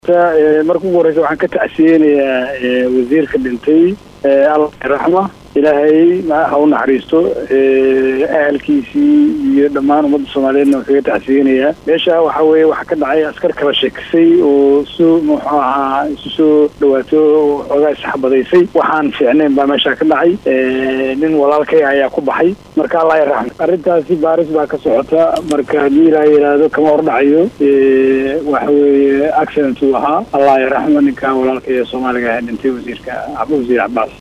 Dr. Nuur Faarax oo wareysi siiyay Idaacada VOA ayaa sheegay inuu ahaa arrin shil ah, isla markaana uu ka tacsiyadeynayo geerida Wasiirka sida uu hadalka u dhigay.